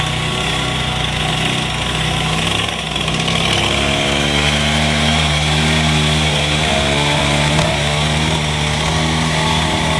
Звук трактора:
tractor1.wav